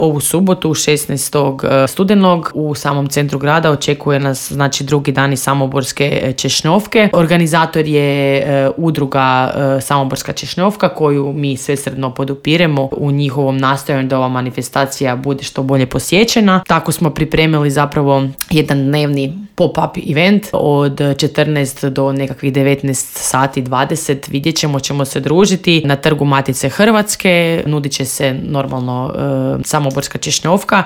Otkrila nam je to u Intervjuu Media servisa